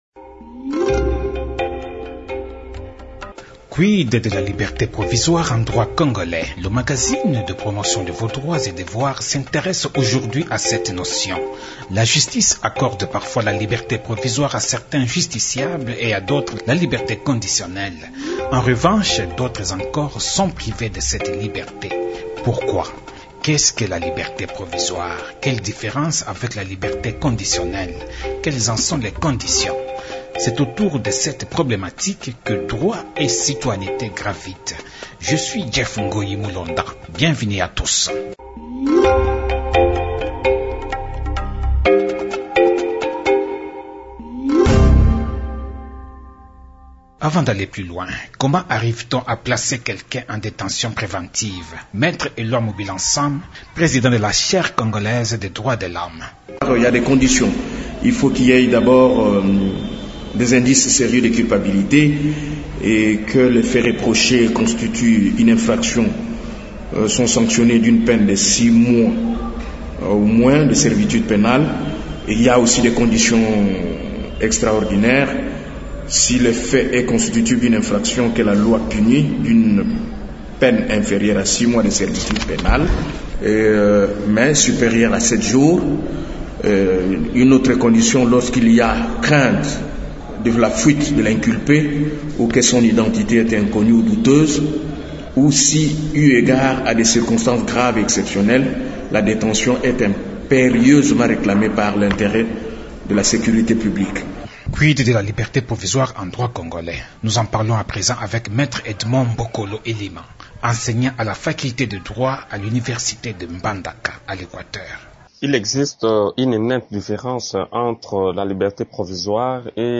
Le magazine pédagogique de promotion des droits et devoirs s’est intéressé à cette question pour se rendre de la façon dont la justice est rendue dans notre pays. Qu’est-ce qu’on entend réellement par cette notion de liberté provisoire ? Qu’est-ce qui la différencie de la liberté conditionnelle ?